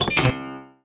Catapult Sound Effect
Download a high-quality catapult sound effect.
catapult.mp3